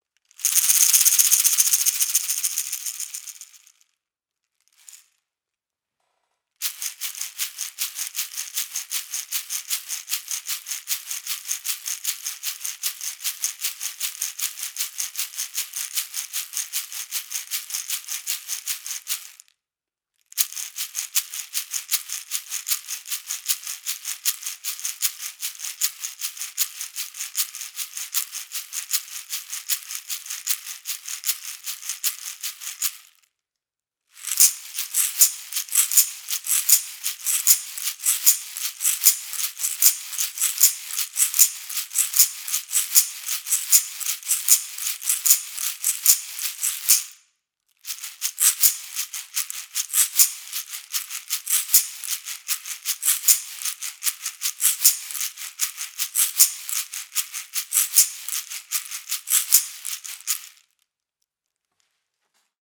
The MEINL Percussion Double Shaker/Ganza is woven by hand. They offer two different sounds in one instrument.